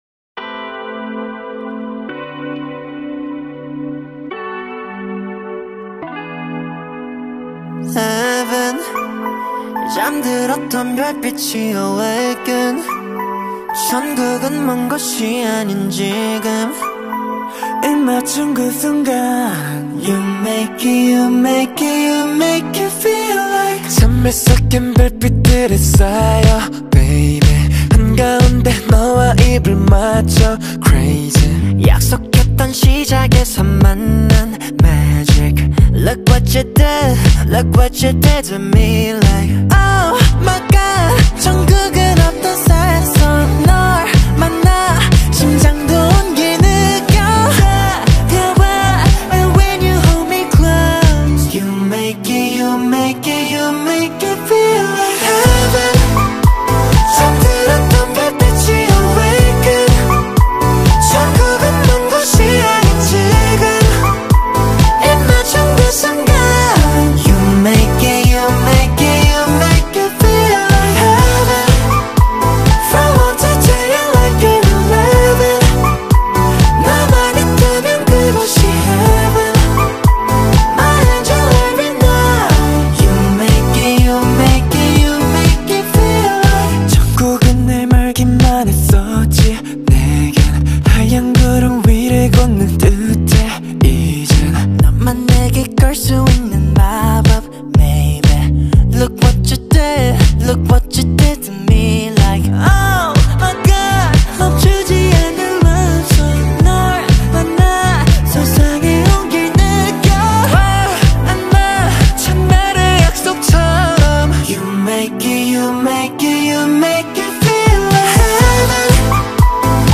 Скачать музыку / Музон / Корейская K-POP музыка 2024